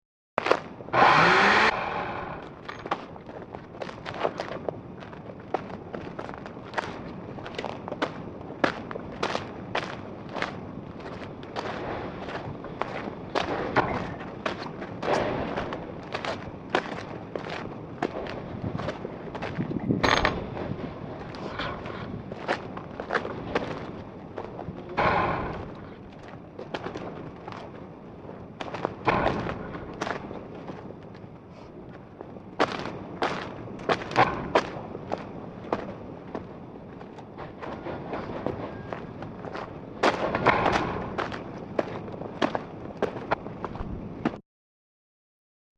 These files may be downloaded and listened to as a very minimal industrial noise album, or may be downloaded for use as above.
All files are stereo mp3s encoded at 128 kpbs, although some files are paired mono tracks, with different sources panned hard left and right.
noise 1 0:45 paired mono 718k
noise1.mp3